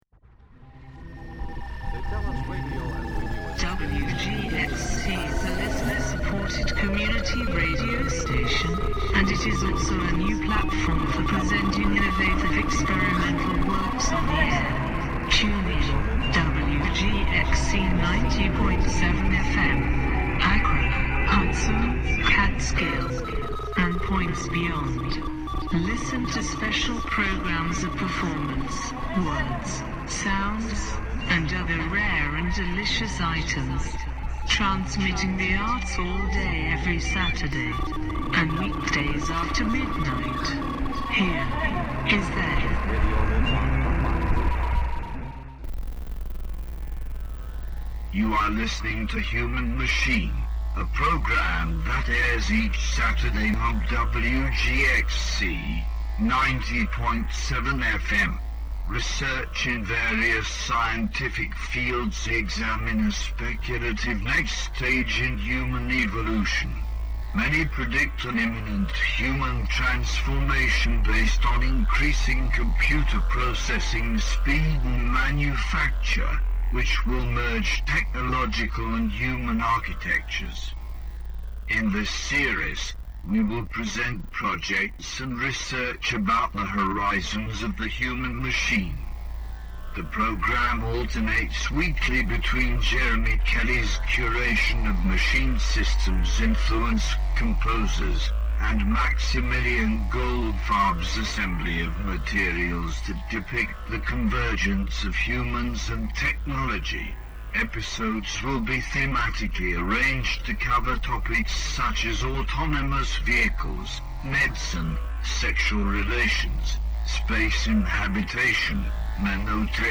Human Machine is a hour-long radio broadcast produ...